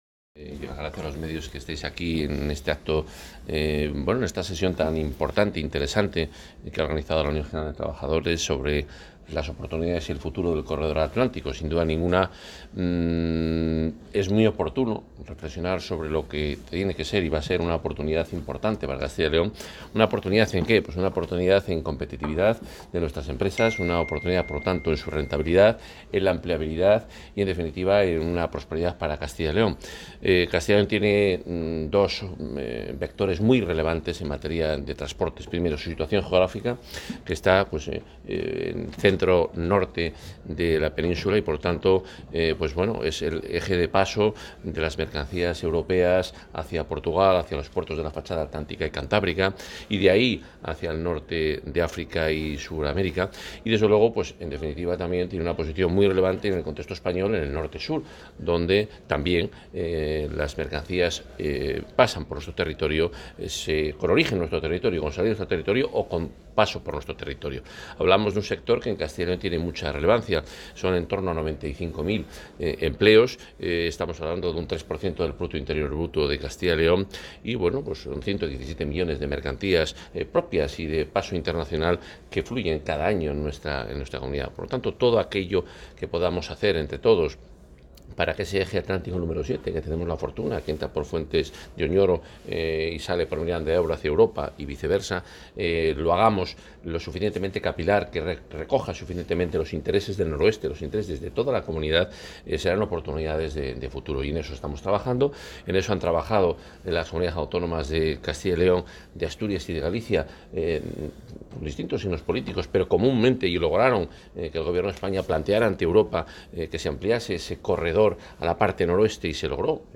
Intervención del consejero de Fomento y Medio Ambiente: Presente y Futuro del Corredor del Atlántico
El consejero de Fomento y Medio Ambiente, Juan Carlos Suárez-Quiñones, ha pronunciado hoy en Valladolid la conferencia ‘Presente y futuro’, dentro del programa de la jornada ‘El Futuro del Corredor Atlantico’, organizada por UGT
DeclaracionesdeSuarez-QuiñonesenelactoorganizadoporUGTsobrelogística.m4a